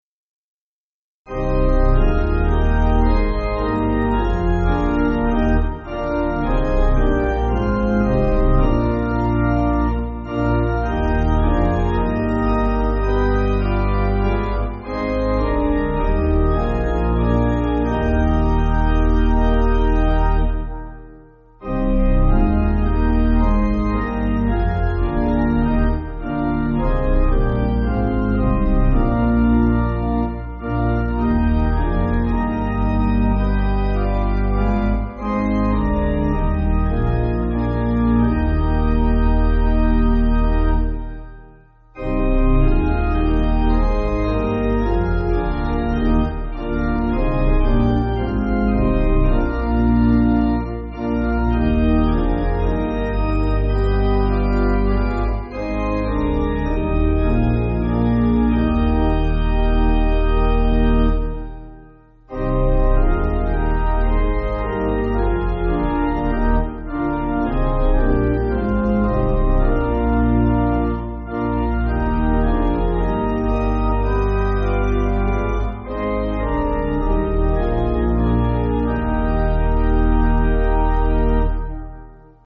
Organ
(CM)   5/Eb